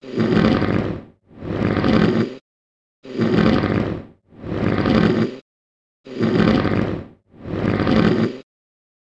1 channel
P56SNORE.mp3